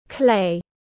Đất sét tiếng anh gọi là clay, phiên âm tiếng anh đọc là /kleɪ/.
Clay /kleɪ/